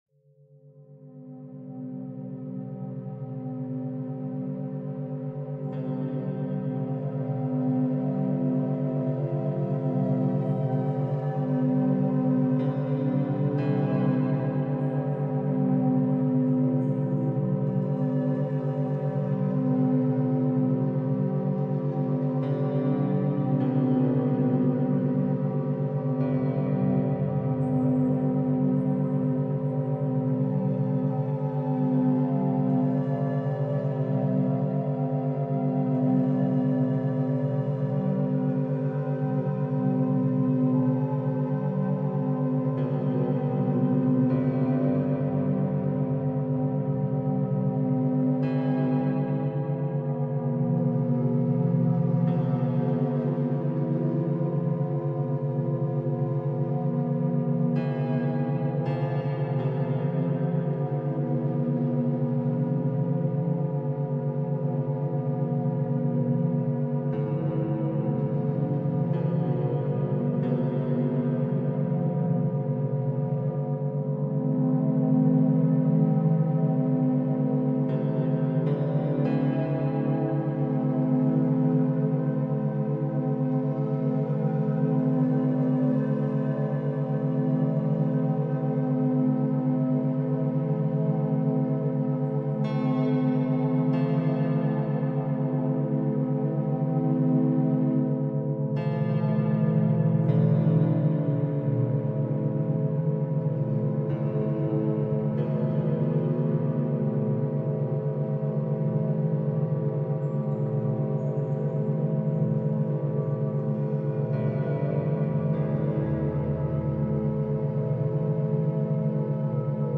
Background Sounds, Programming Soundscapes